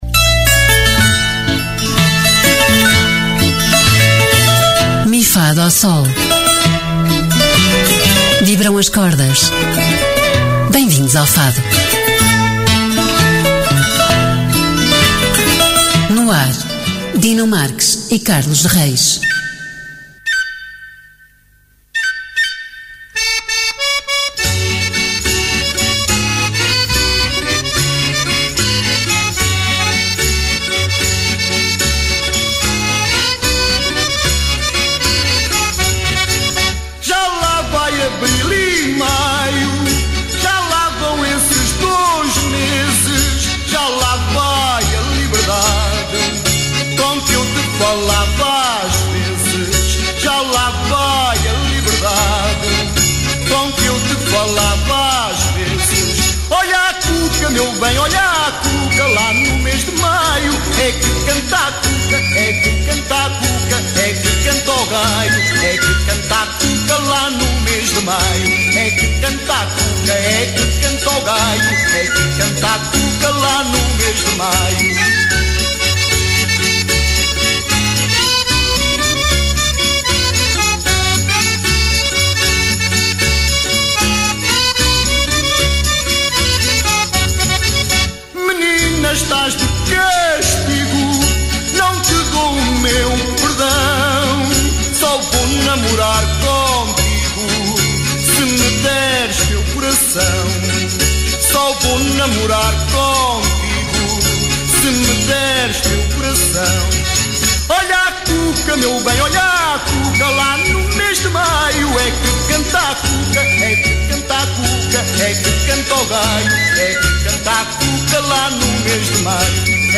Emissão: 21 de Dezembro 2023 Descrição: Programa inteiramente dedicado ao Fado. Realizado por intérpretes do género musical, Mi-Fá-Dó-Sol foca-se na divulgação de artistas e eventos, sem deixar de lado a história e as curiosidades de tão importante património português!